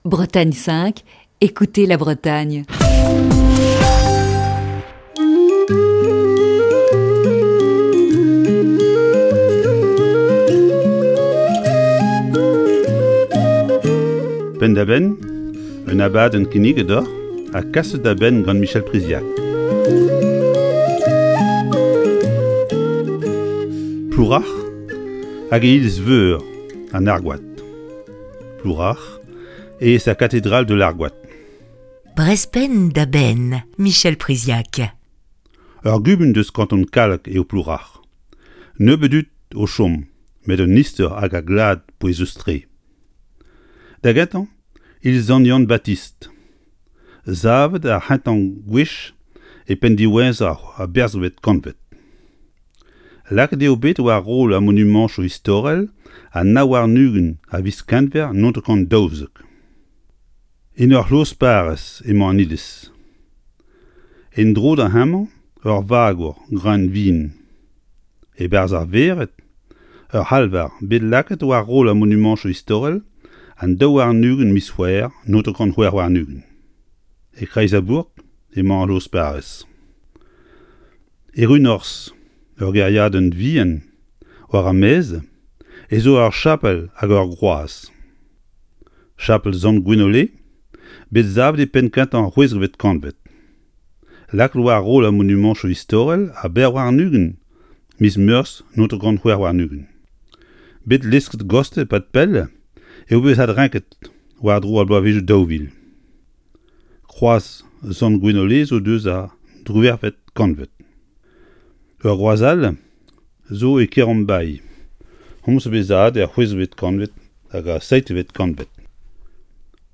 Chronique du 15 avril 2020.